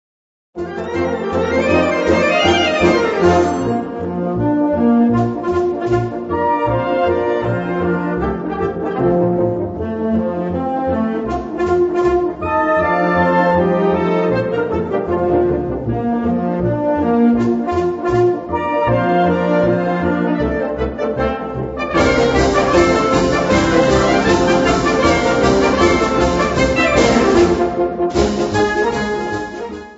Gattung: Galopp
Besetzung: Blasorchester